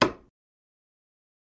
Chisel and Hammer, Single Hit